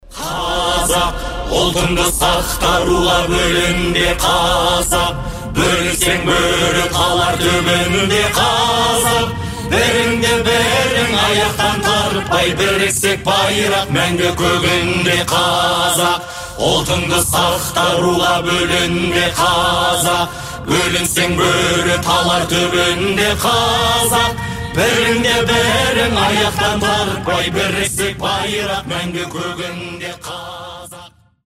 Хор
Акапелла